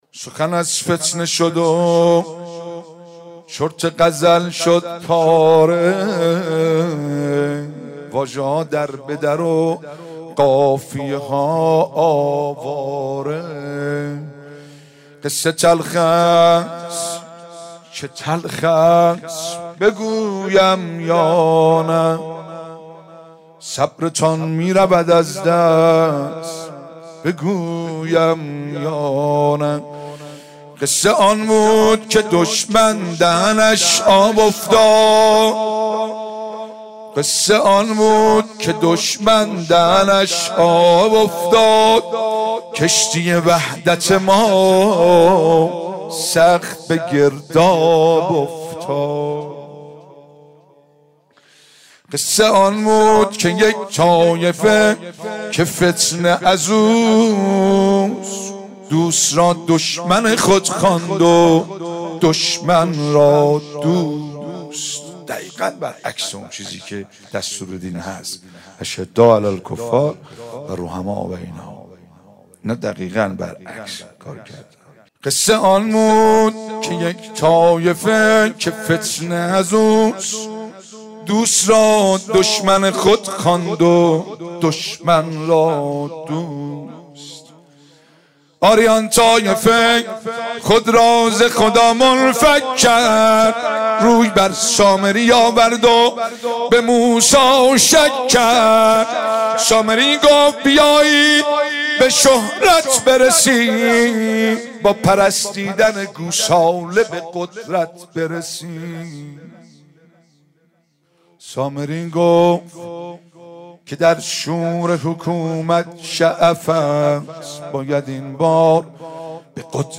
نهم دی 95_شعرخوانی_حماسی سخن از فتنه شد